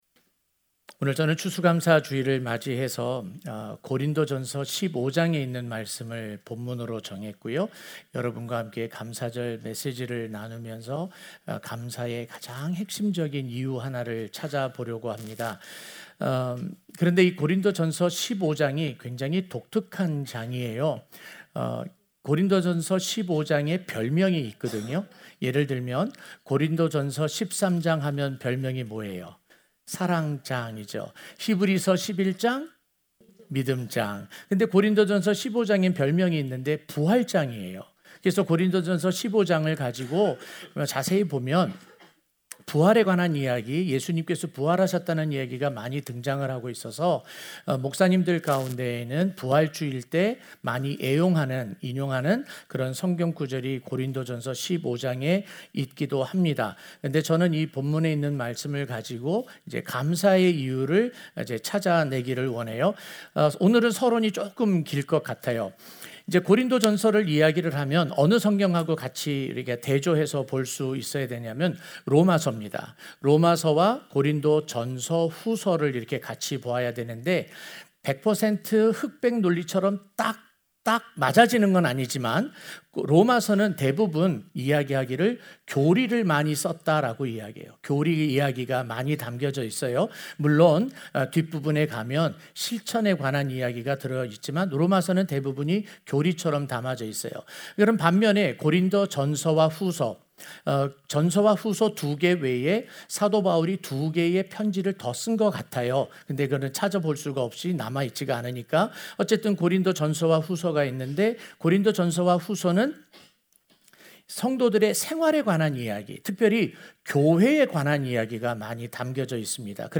하나님의 은혜-추수감사주일 설교
주일설교